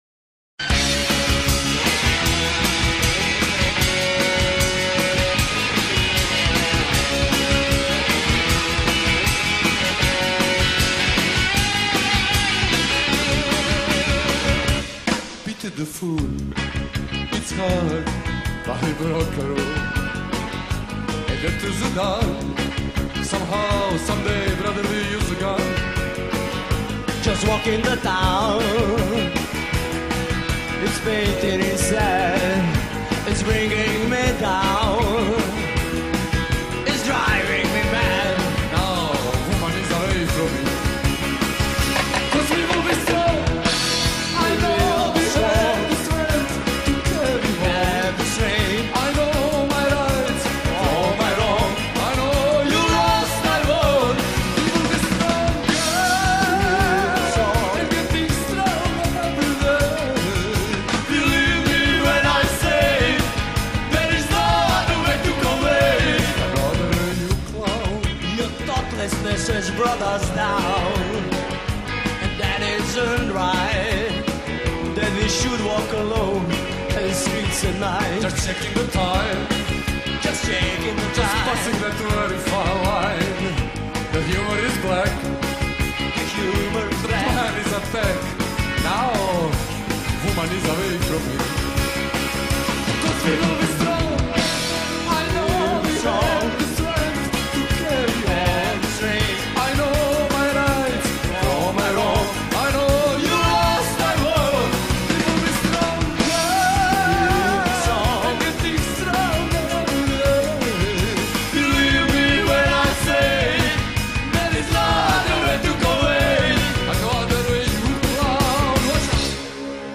- Reprises et Covers -